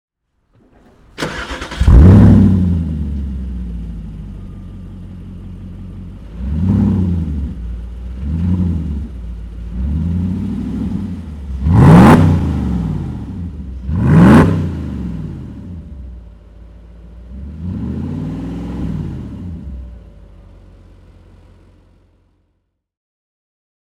Motorsounds und Tonaufnahmen zu Spyker C8 Laviolette Fahrzeugen (zufällige Auswahl)
Spyker C8 Spyder (2008) - Starten und Leerlauf
Spyker_C8.mp3